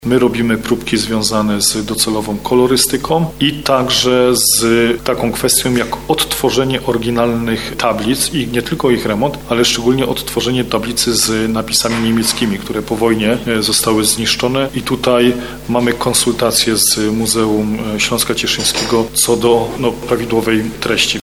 Tak mówi zastępca burmistrza Cieszyna Przemysław Major o docelowym kolorze studni Trzech Braci.
Konieczna jest także przebudowa zadaszenia studni Trzech Braci – informuje zastępca burmistrza Cieszyna.